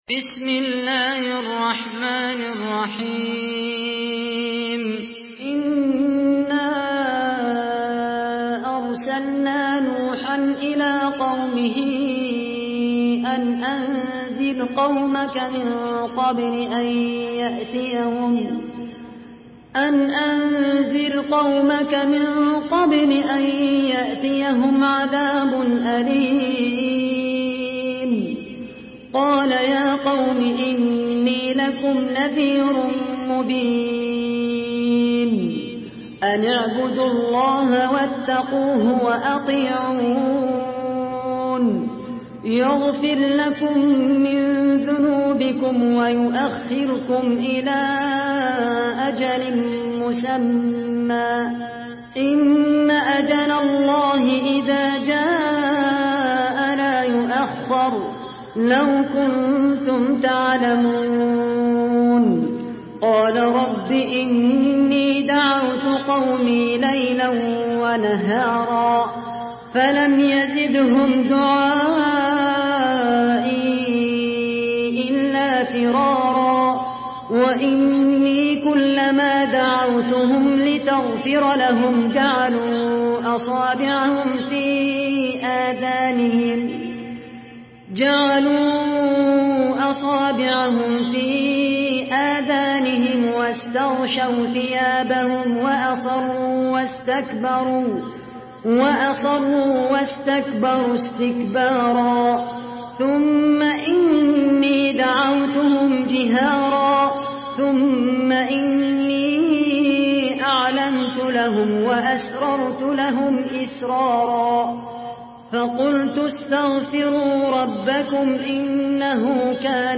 Surah Nuh MP3 in the Voice of Khaled Al Qahtani in Hafs Narration
Murattal